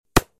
hammerWood.mp3